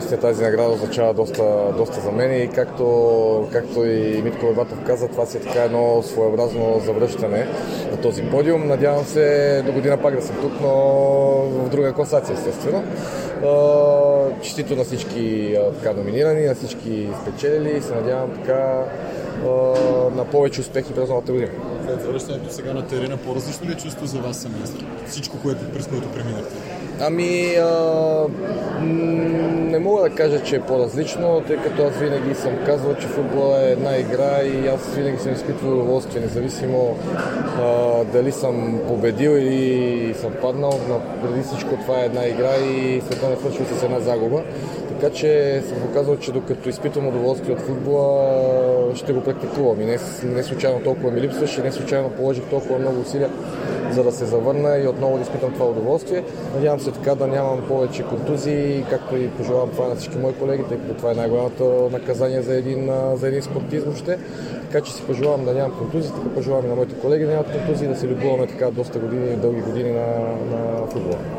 Вратарят Владислав Стоянов получи приза "Лъвско сърце", който бе част от церемонията "Футболист на годината". Този приз му бе връчен заради завръщането му на терена след близо 3-годишно отсътвтие.